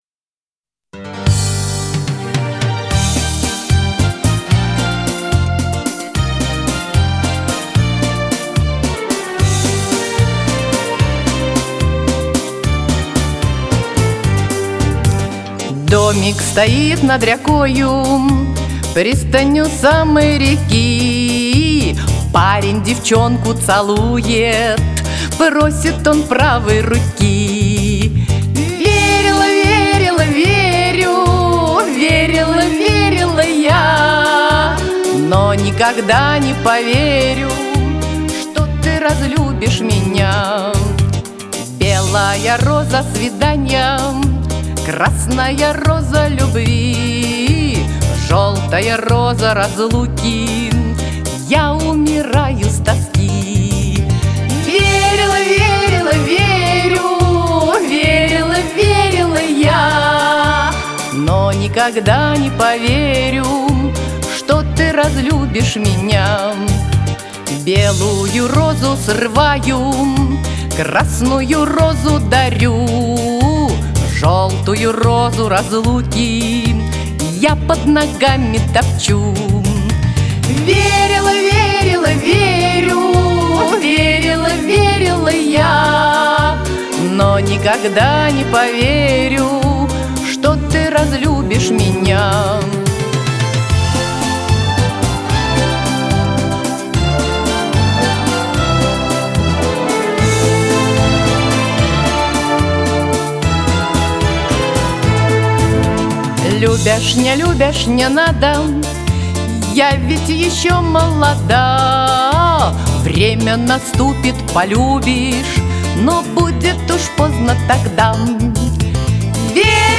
Романсы, городской фольклор и народные песни.